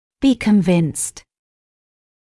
[biː kən’vɪnst][биː кэн’винст]быть убежденным